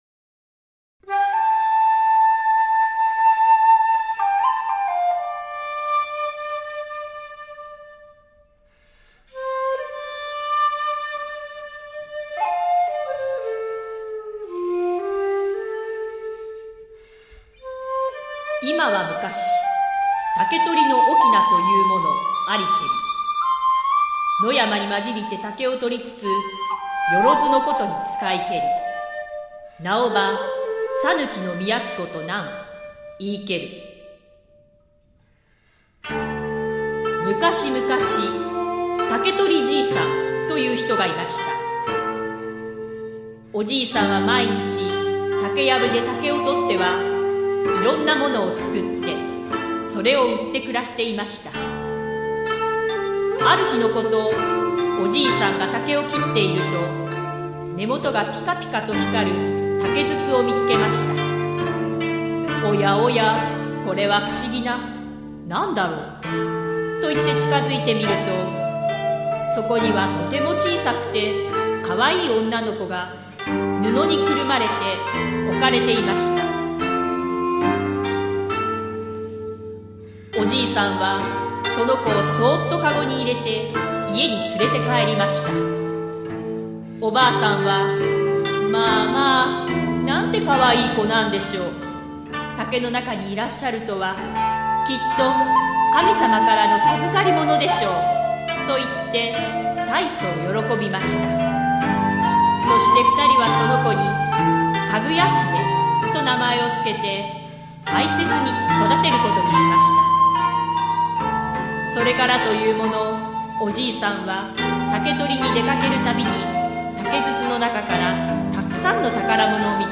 箏1
箏2
十七絃
尺八
語り